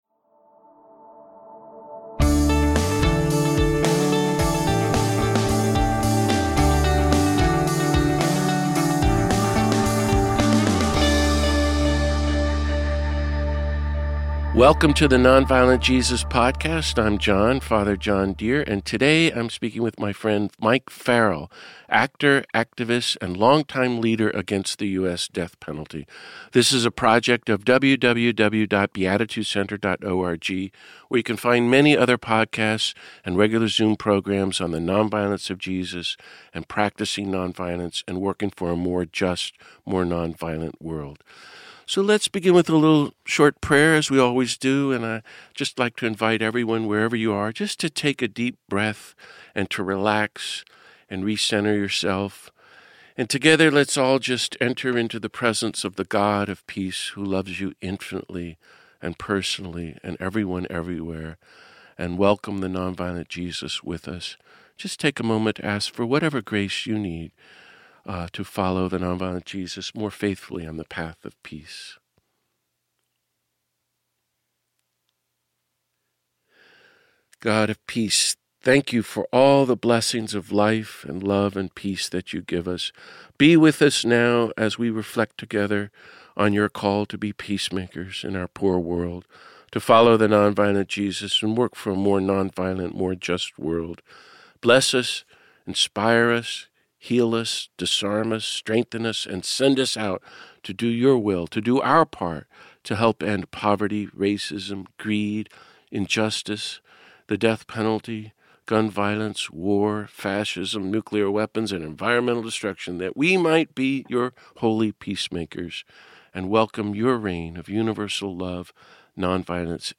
During our conversation, I am constantly impressed by his openheartedness and humanity.